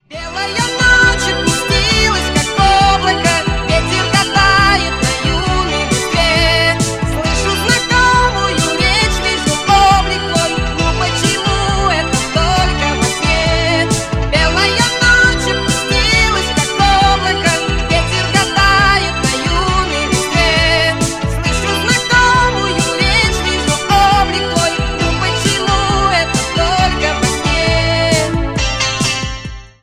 80-ые , поп , ретро